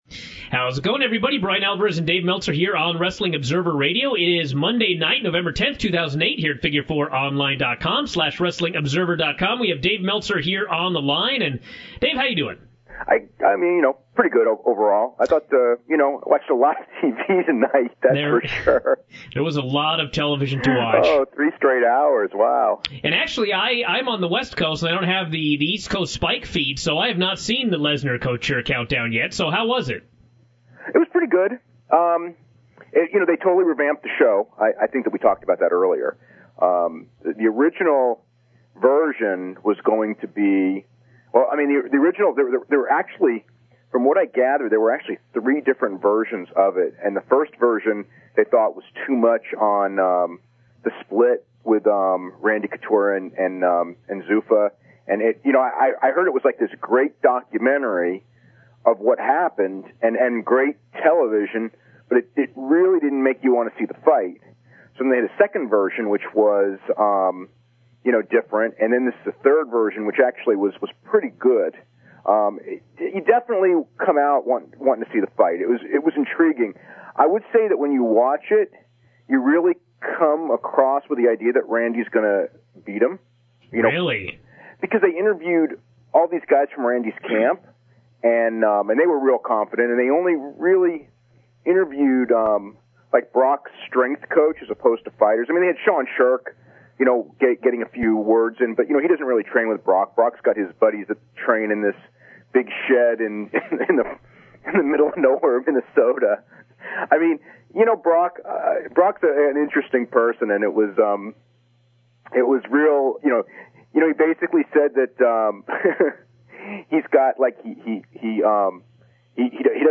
It is now officially comedy.
Replacement BRAND NEW phone-in equipment will arrive on Wednesday and I will go as far as to GUARANTEE that all of my audio problems will be solved -- otherwise I'm shutting down the website.